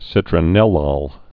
(sĭtrə-nĕlôl, -ōl, -ŏl)